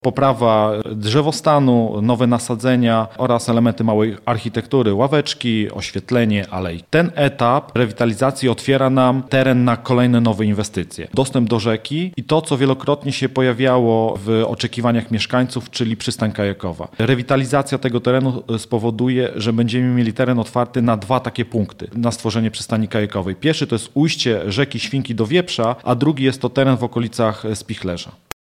– Jednocześnie rewitalizujemy zabytkowy Park Podzamcze – mówi burmistrz Leszek Włodarski.